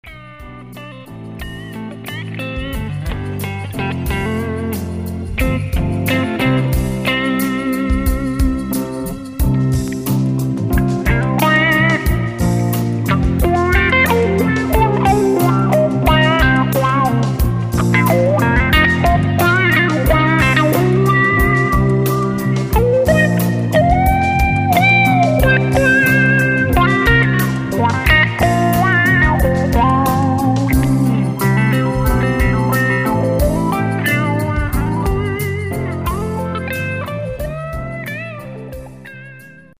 Ich spiel zur Zeit ein MEK Crazy Horse Wah mit regelbarer Q-Frequenz, toller Spule, wirklich perfekt an sich und das vor allem im Clean.